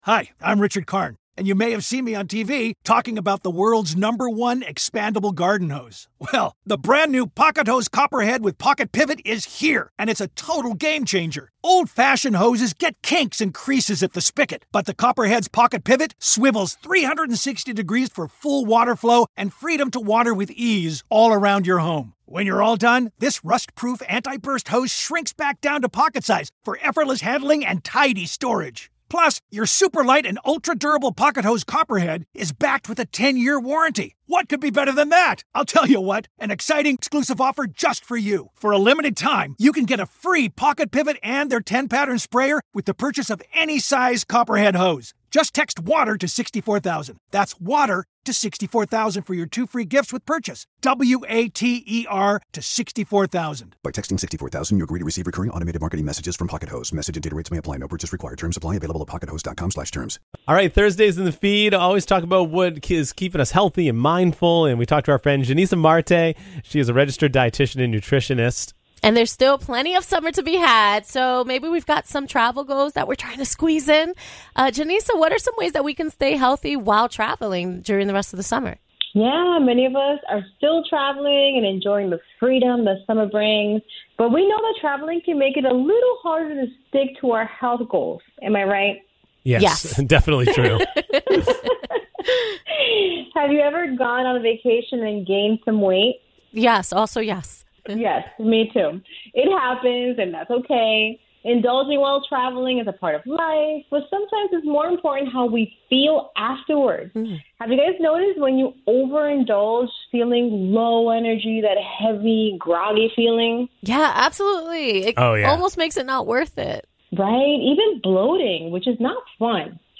chat with an expert about all things health and wellness.